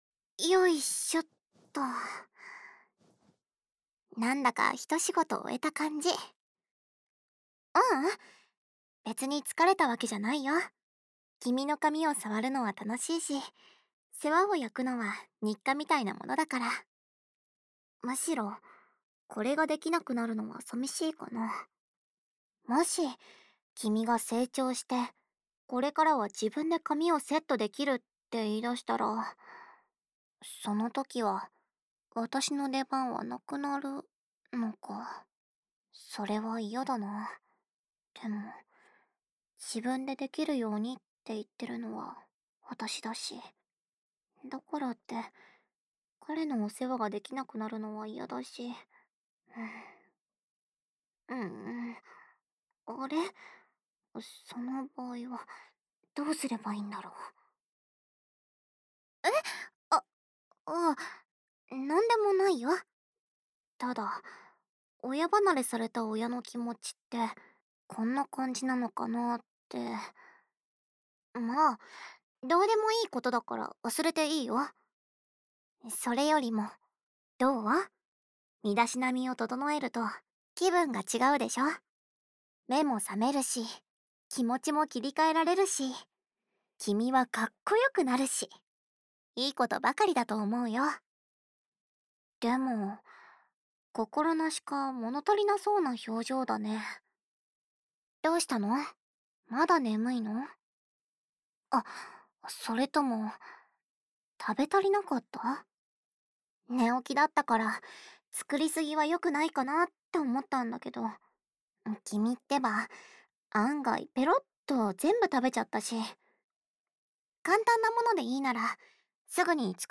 纯爱/甜蜜 温馨 治愈 掏耳 环绕音 ASMR 低语
el97_04_『食後の休憩時間にお耳を癒してあげるね』（耳かき・耳吹き）.mp3